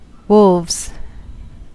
Ääntäminen
Ääntäminen US Tuntematon aksentti: IPA : /ˈwʊlvz/ US : IPA : /ˈwl̩vz/ also: IPA : /ˈwl̩vz/ Haettu sana löytyi näillä lähdekielillä: englanti Käännöksiä ei löytynyt valitulle kohdekielelle.